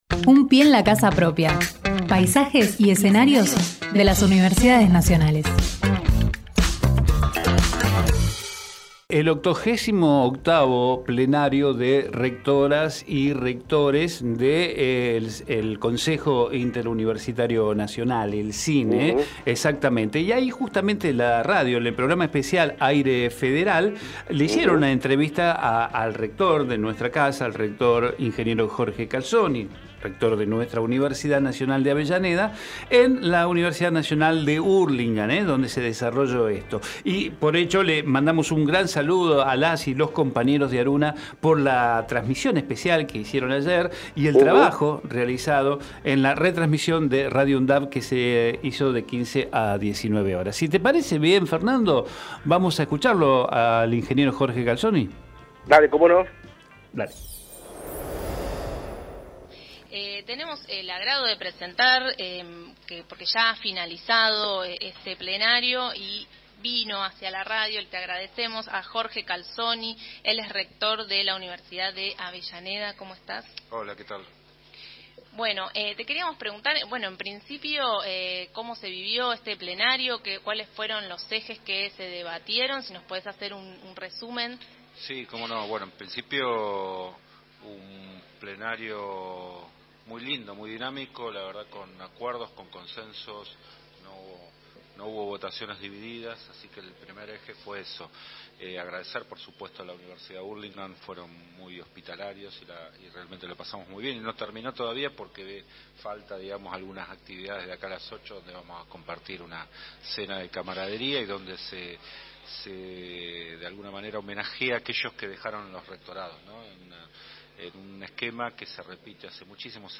Jorge Calzoni en Hacemos PyE Texto de la nota: Compartimos la entrevista realizada al rector de la Universidad Nacional de Avellaneda Ing. Jorge Calzoni en el programa especial AIRE FEDERAL en el 88° Plenario de Rectoras y Rectores del Consejo Interuniversitario Nacional (CIN), una transmisión especial de ARUNA en la Universidad Nacional de Hurlingham (UNAHUR) con la retransmisión de RADIO UNDAV.